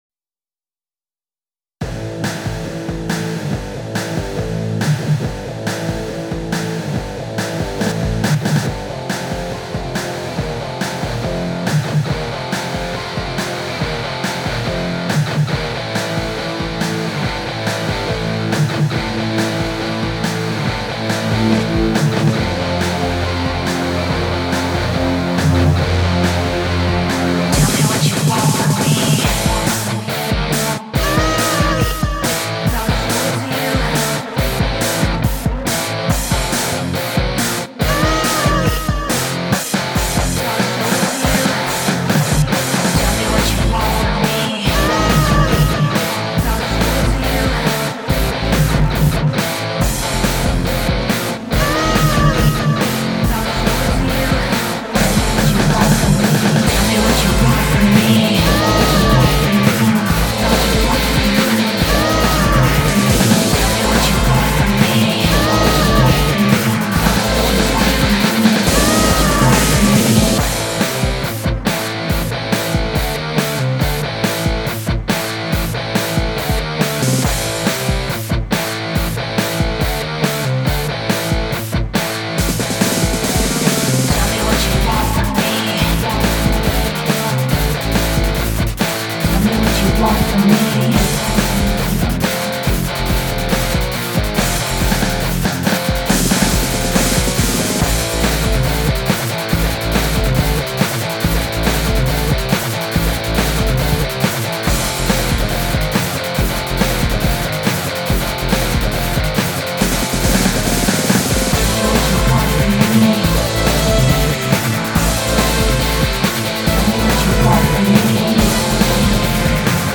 Rocksong in FL Studio
Gemacht in FL Studio mit FL Studio Vocals und einem integrierten Rockgitarre-Tool.